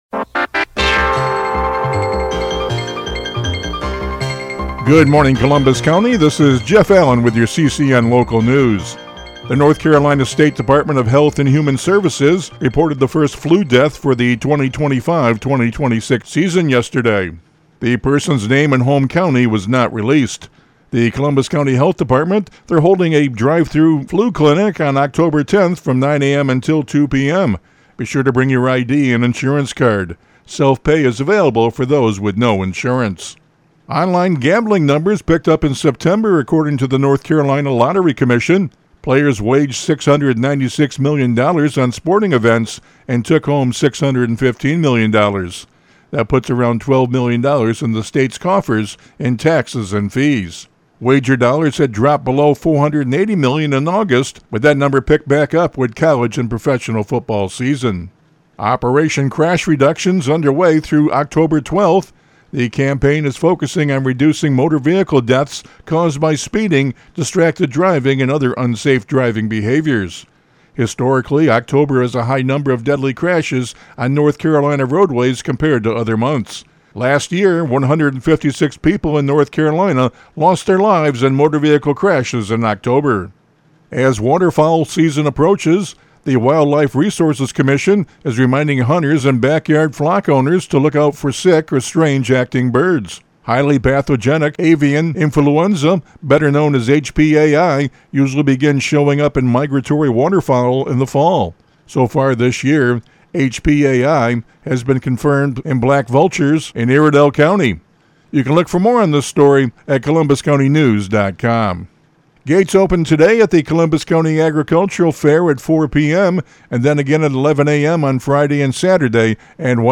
CCN Radio News — Morning Report for October 9, 2025